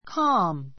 ca l m kɑ́ːm カ ー ム 形容詞 穏 おだ やかな, 静かな, 落ち着いた ⦣ 天候や海の状態についても人の様子や心の状態などについてもいう. a calm day [sea] a calm day [sea] 穏やかな日[海] Mr. Smith is always calm.